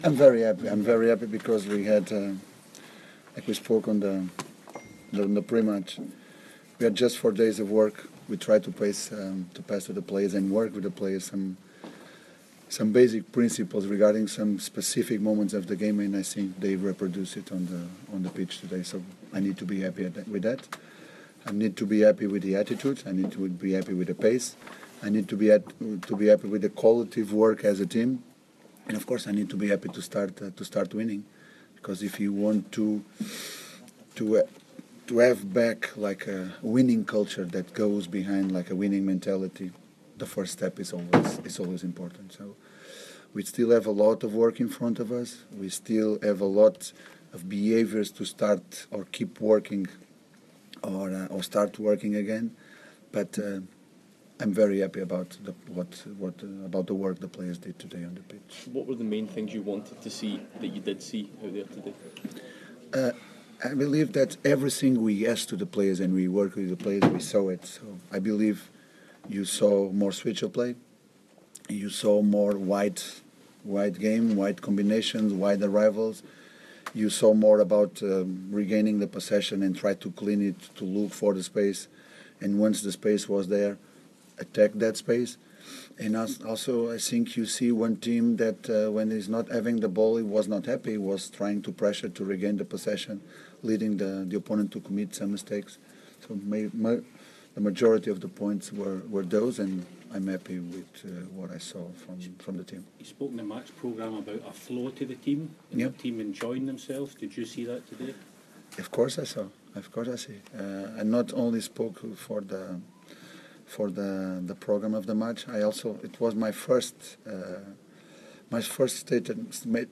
Pedro Caixinha's Post Match Interview 18th March 2017
Rangers beat Hamilton 4-0 in Pedro Caixinha's first match in charge of Rangers. Listen to what he and midfielder John Toral had to say after the match.